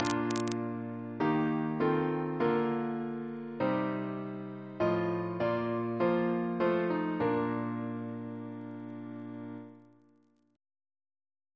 Single chant in B♭ Composer: Haydn Keeton (1847-1921) Reference psalters: ACB: 17